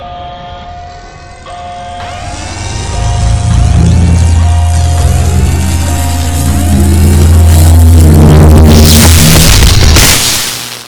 neovgre_exploding.ogg